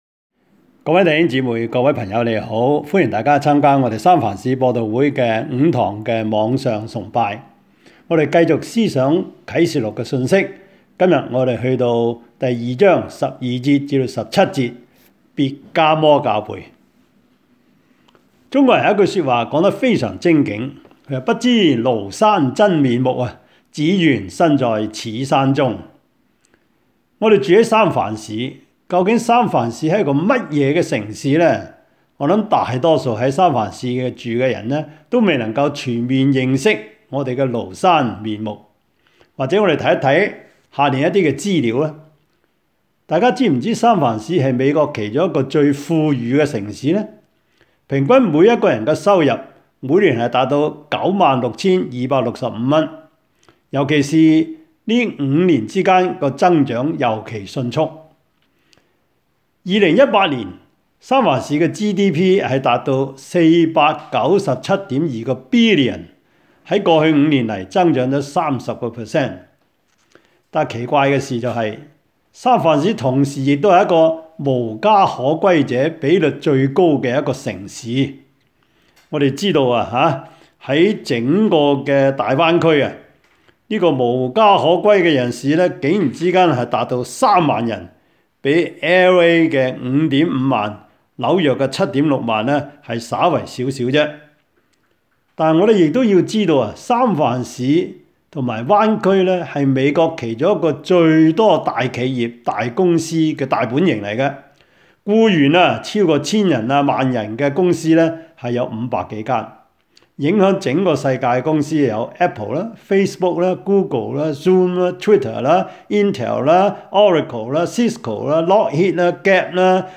Series: 2020 主日崇拜